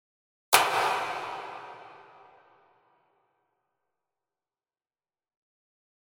今回はプリセットのLarge Hall Brightというものをベースに画像のような設定にしております。
クラップ音（リバーブ有）